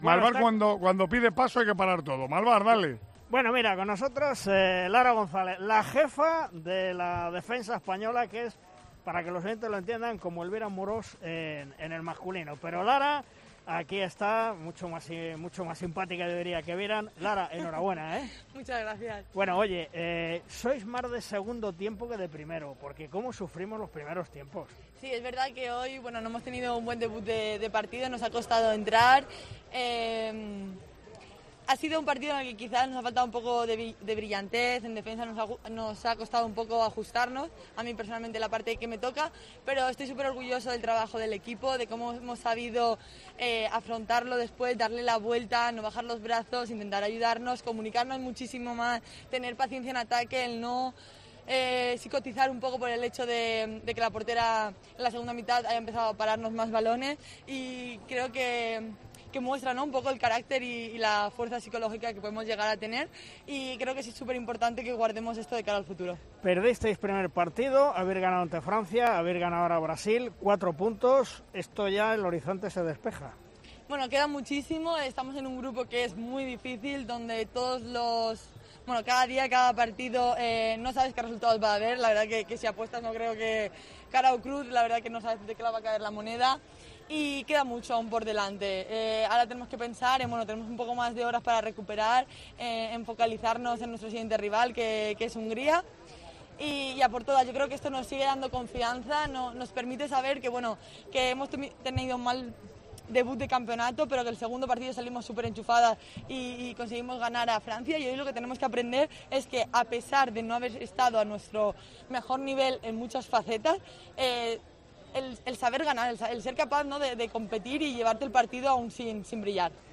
La jugadora de la selección española de balonmano femenino ha atendido a Tiempo de Juego tras la victoria de las Guerreras sobre Brasil.